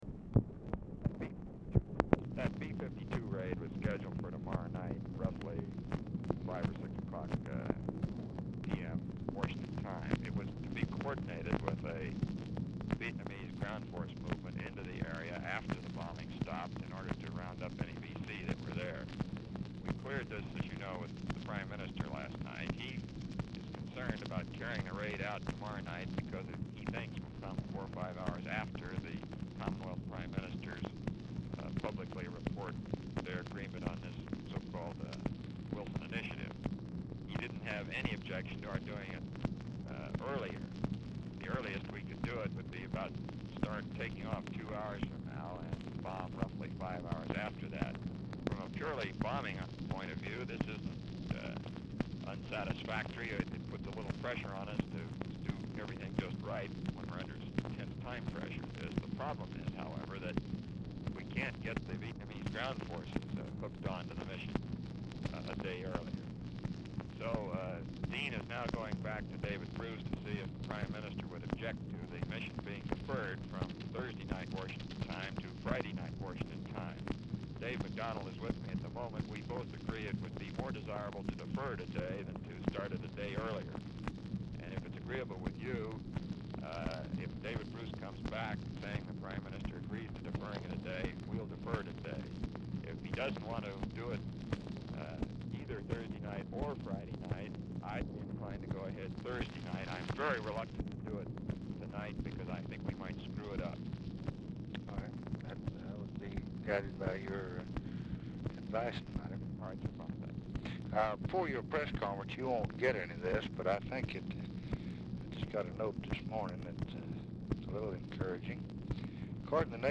Oval Office or unknown location
"SUMMARIZED"; "RE B-52 RAID SCHEDULED FOR TOMORROW IN VNAM-SECY ADVISED CLEARED W/BRITISH PRIME MINISTER AND EUROPEAN POLLS ON VNAM"; RECORDING STARTS AFTER CONVERSATION HAS BEGUN; POOR SOUND QUALITY
Telephone conversation
Dictation belt